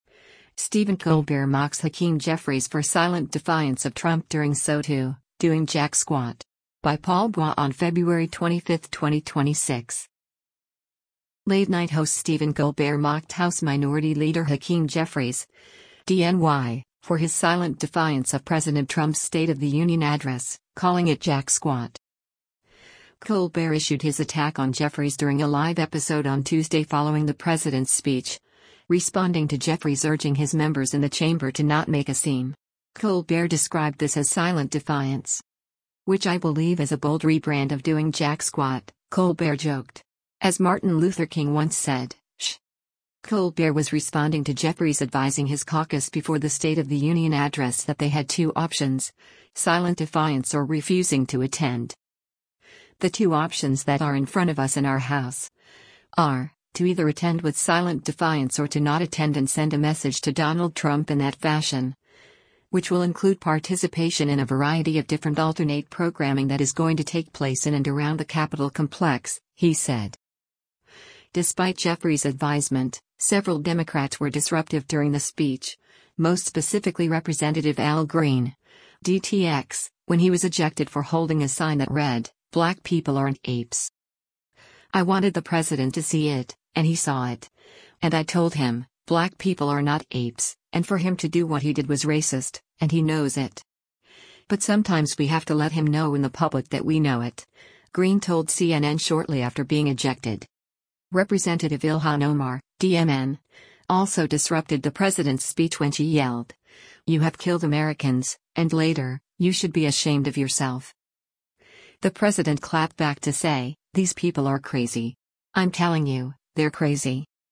Colbert issued his attack on Jeffries during a live episode on Tuesday following the president’s speech, responding to Jeffries urging “his members in the chamber to not make a scene.”